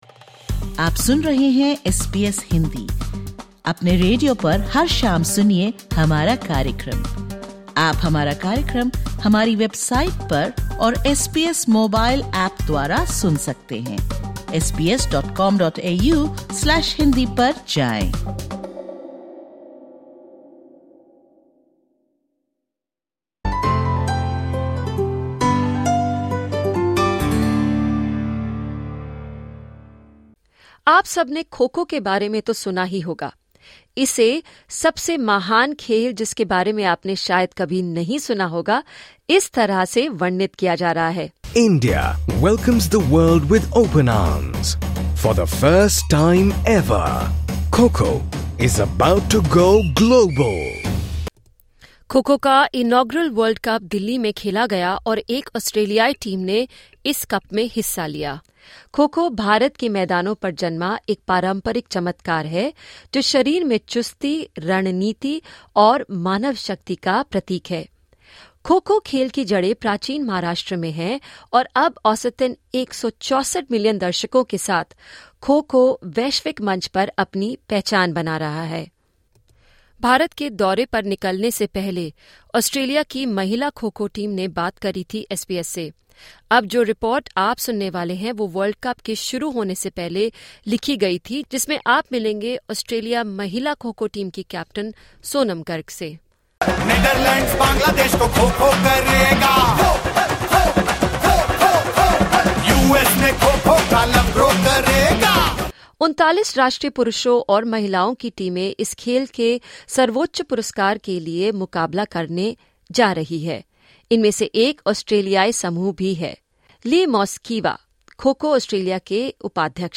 सुनें ऑस्ट्रेलिया और भारत से २४/०१/२०२५ की प्रमुख खबरें।